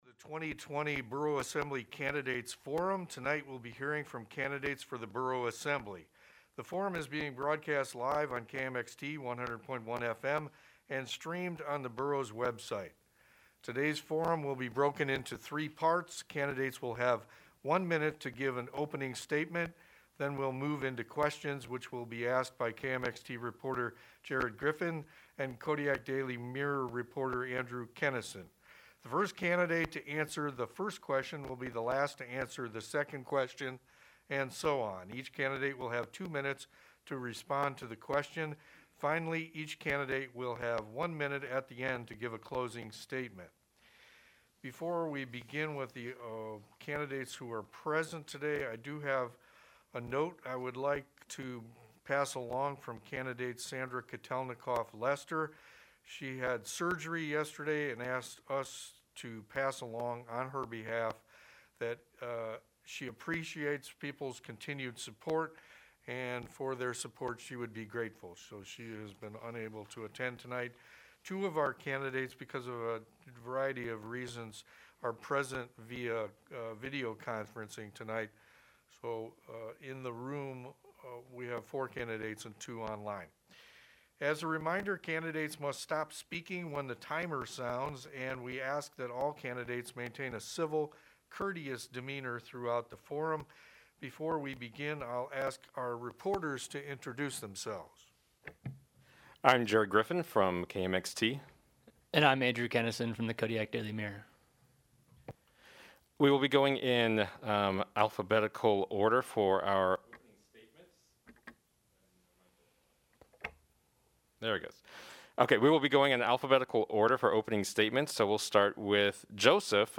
Borough Assembly Candidate Forum
Five of the seven candidates for the Borough Assembly weighed in on the issues in a forum hosted by KMXT and Kodiak Daily Mirror on Tuesday, September 29, 2020.